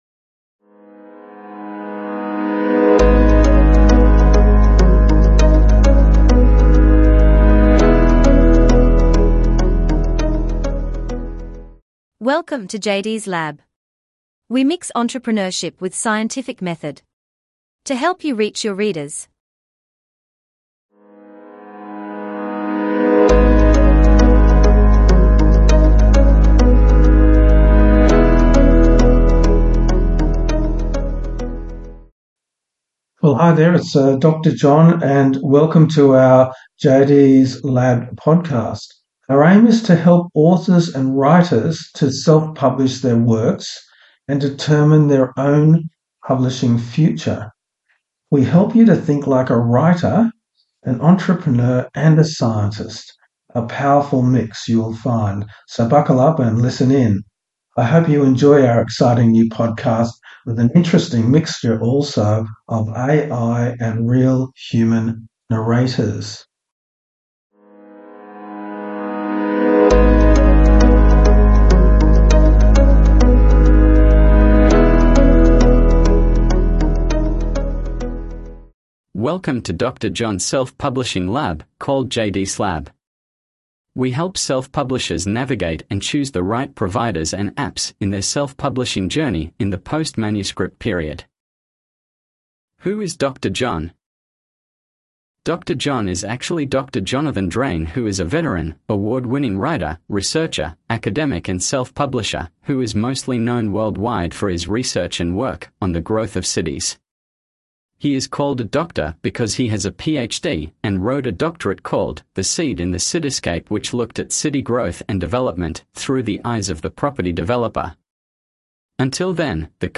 I hope you enjoy our exciting new podcast with an interesting mixture of AI and real human narrators.